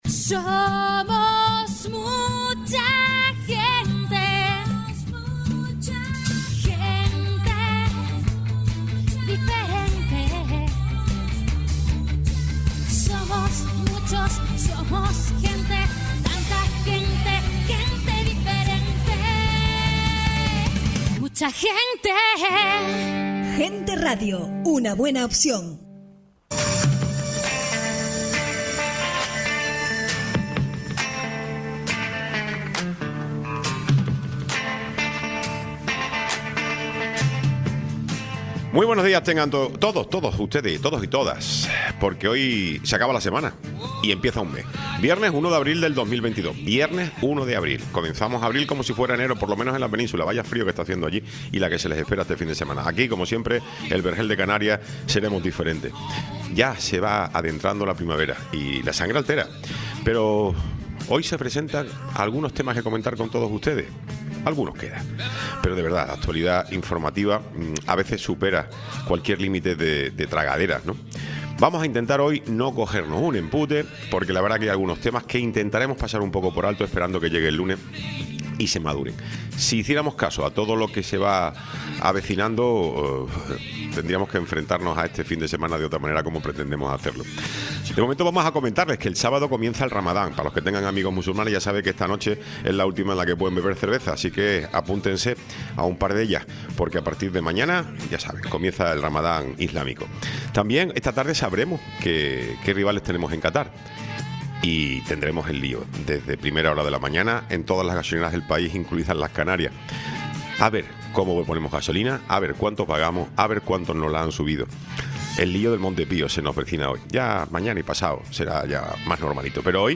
Tiempo de entrevista
Tertulia de mujeres en política